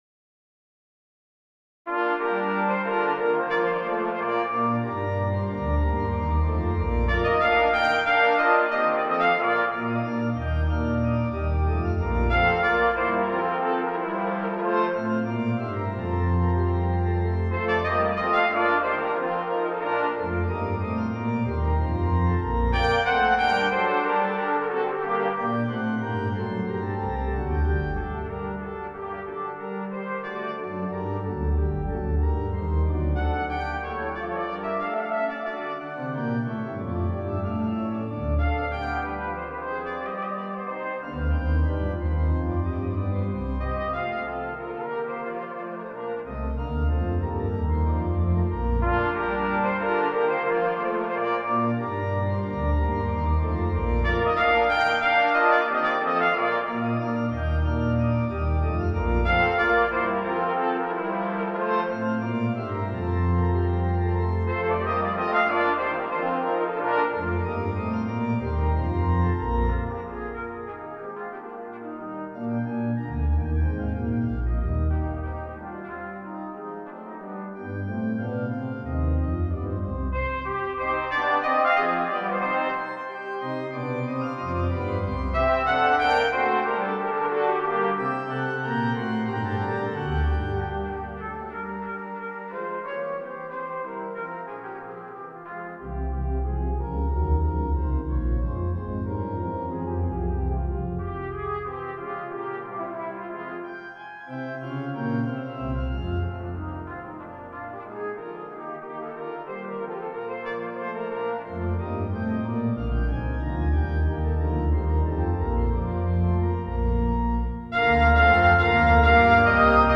Voicing: Brass Quartet and Organ